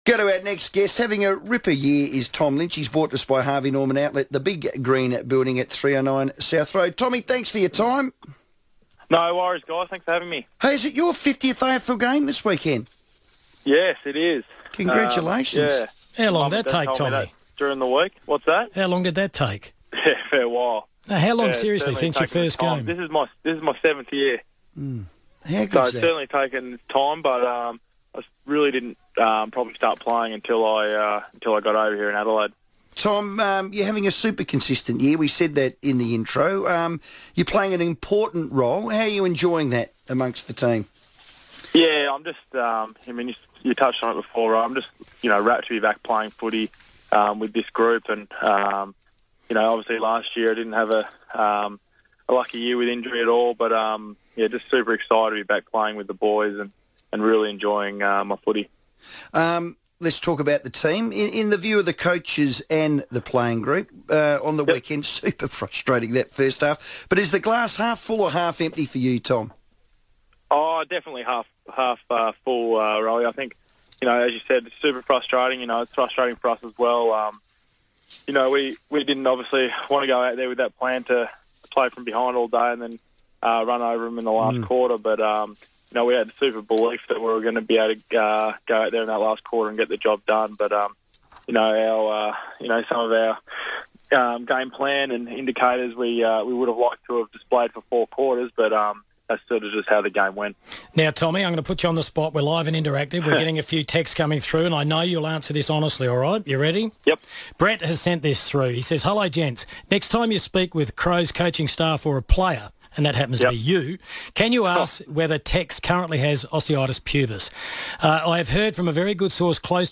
Tom Lynch joined the FIVEaa Sports Show ahead of his 50th AFL game on Sunday against Geelong.